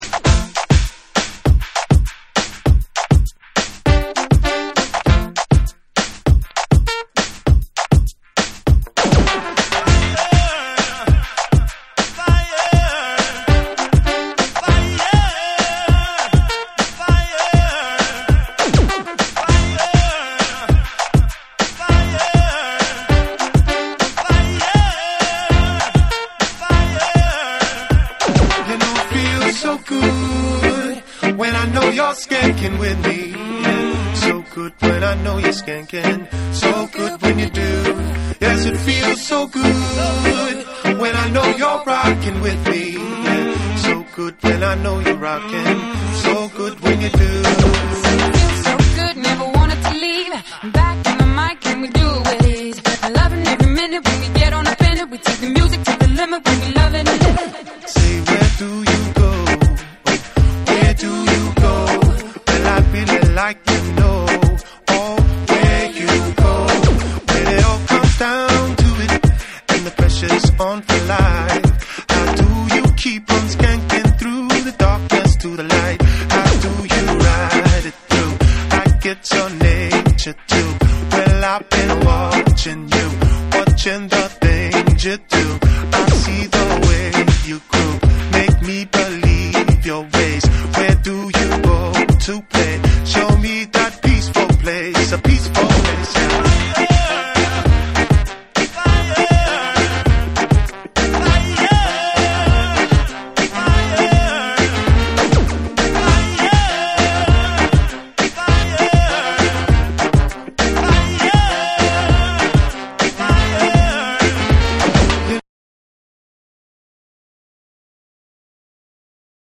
ほっこりしたシンセと、裏打ちが効いたトラックに、彼等らしいレイドバックした空気感が融合した1。
BREAKBEATS / REGGAE & DUB